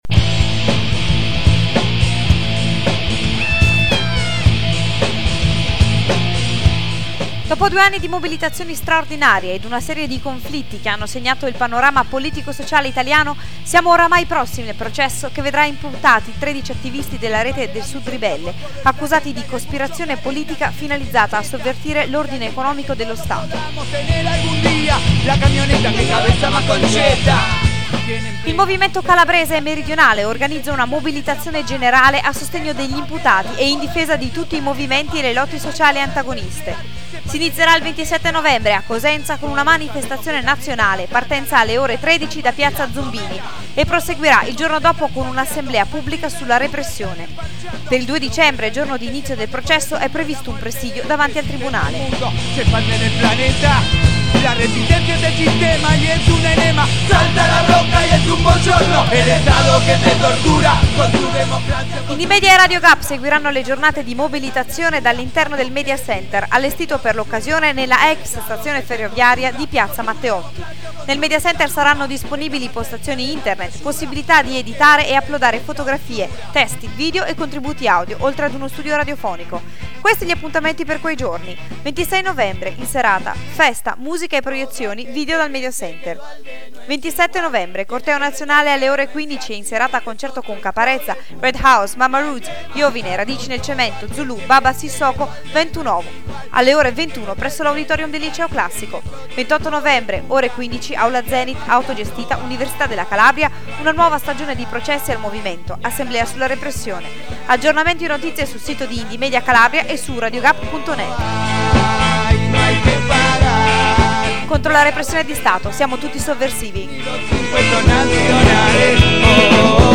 SPOT RADIO, per la manifestazione contro i processi al "Sud Ribelle", a Cosenza il 27 novembre